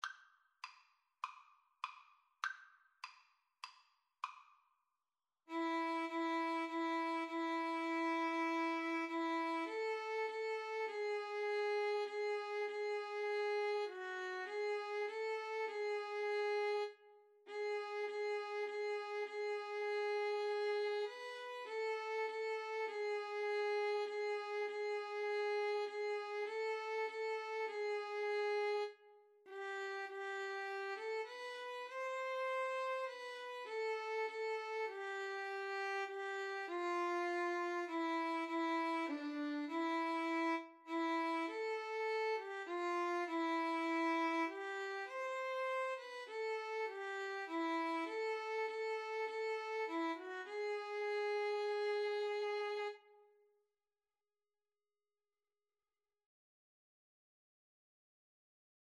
4/4 (View more 4/4 Music)
Violin-Cello Duet  (View more Easy Violin-Cello Duet Music)
Classical (View more Classical Violin-Cello Duet Music)